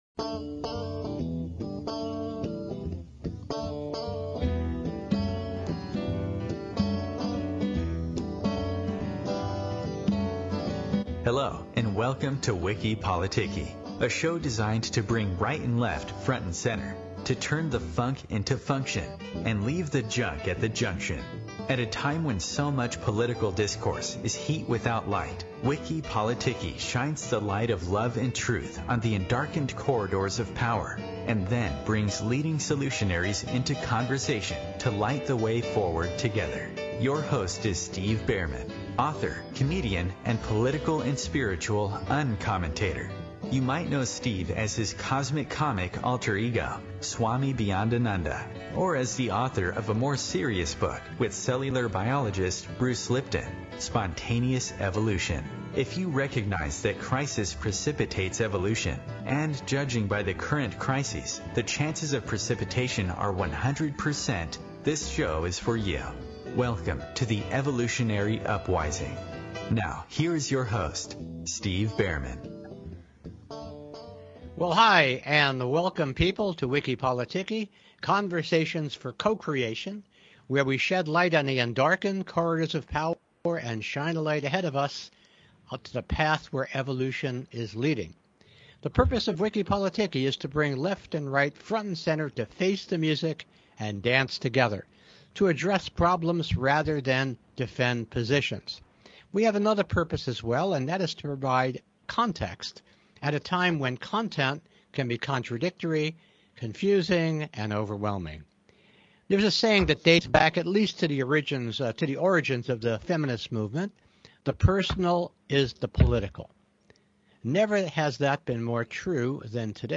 A December 25, 2018 Rebroadcast - Originally Aired Tuesday, 18 December 2018, 5:00 PM EST